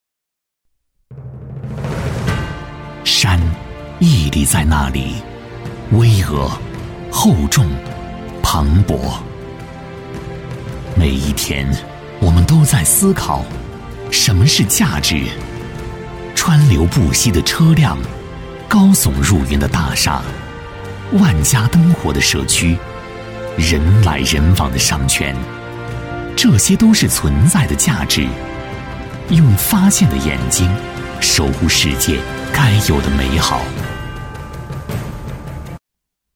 配音风格： 磁性，年轻
【专题】致山